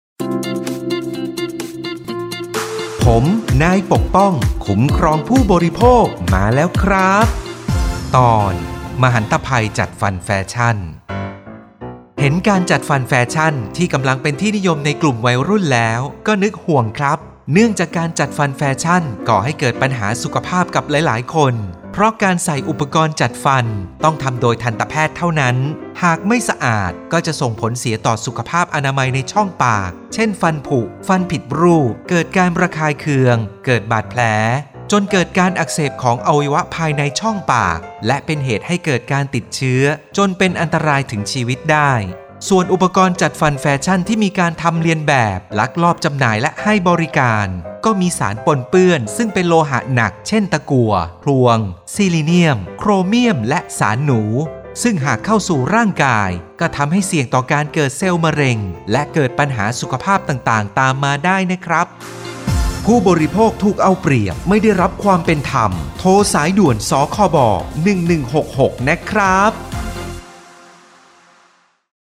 สื่อประชาสัมพันธ์ MP3สปอตวิทยุ ภาคกลาง
002.สปอตวิทยุ สคบ._ภาคกลาง_เรื่องที่ 2___.mp3